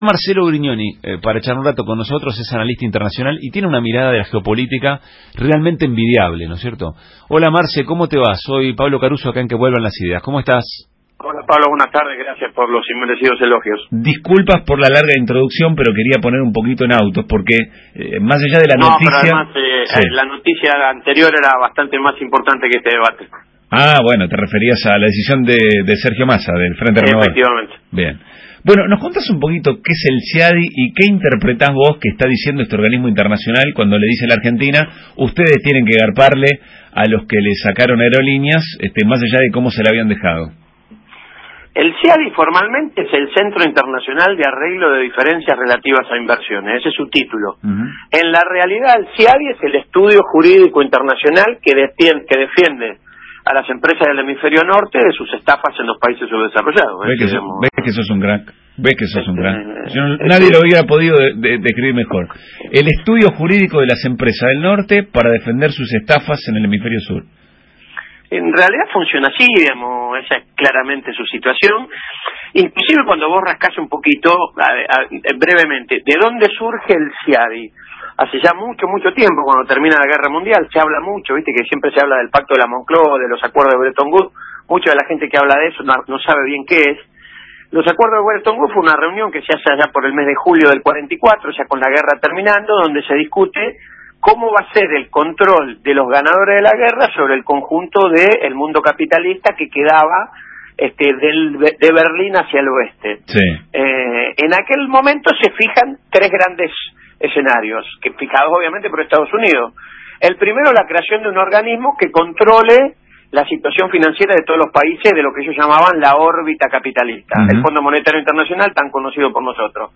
Entrevista en el Programa Que Vuelvan las Ideas de Radio AM 750